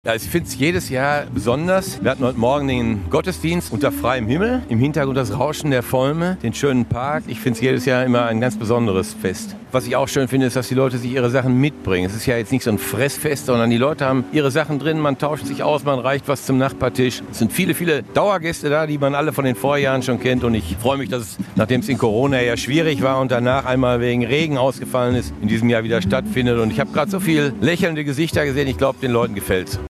Erik Olaf SchulzOberbürgermeister an der Volme